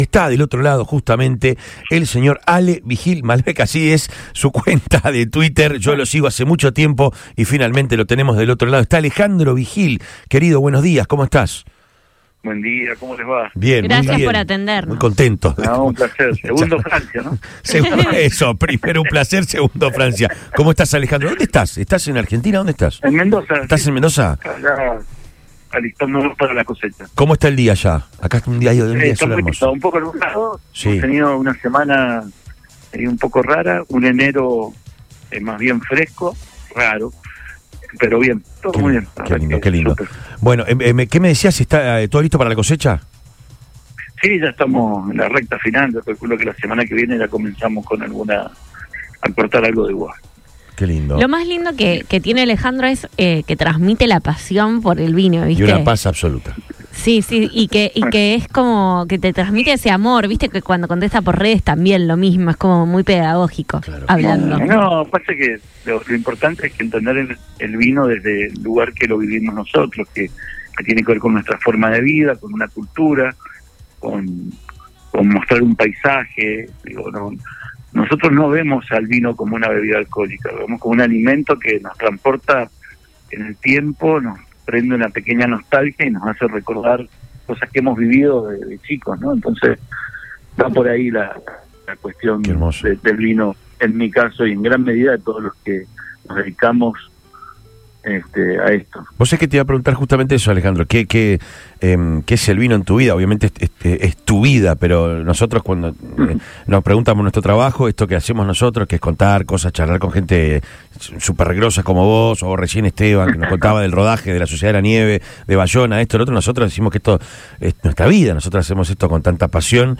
En dialogo con Lo Mejor de Todo de Radio Boing